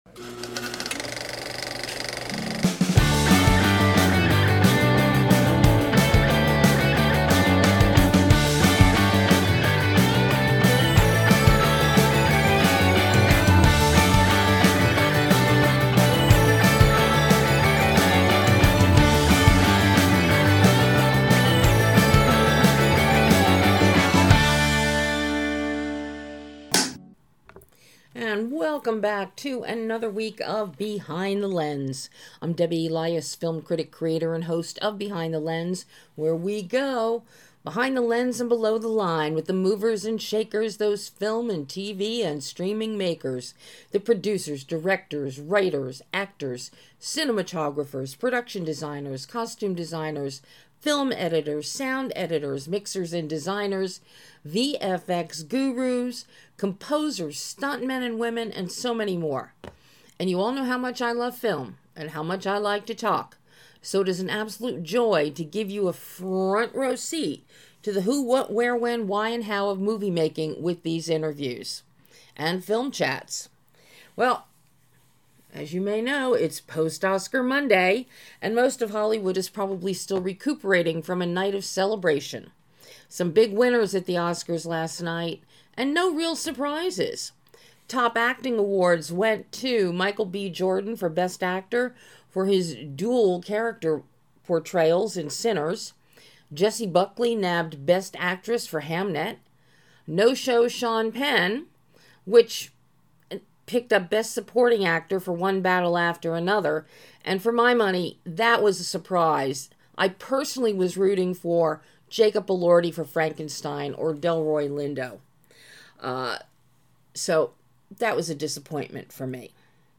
In this exclusive interview, David discusses adapting his novel “Cold Storage” into a film, highlighting the challenges of preserving the book’s humor and snark while simplifying complex scientific concepts.